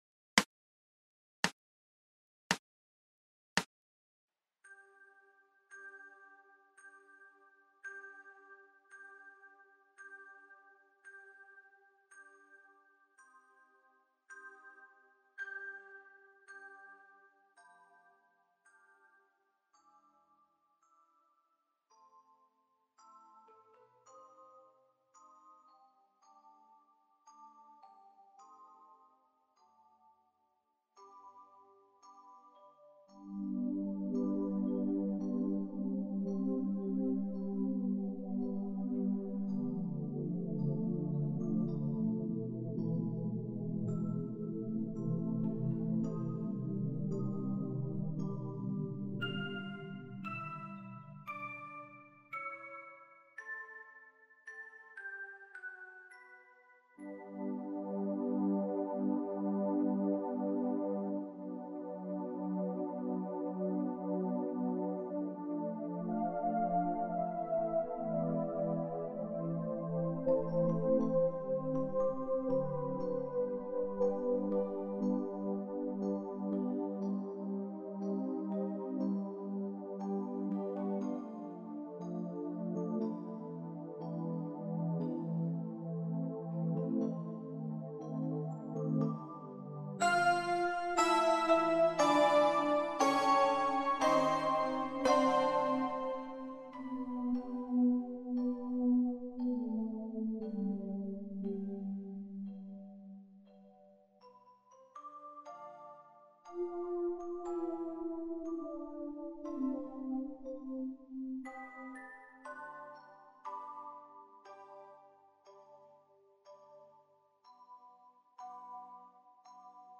SYNTH KEYS (Descargar)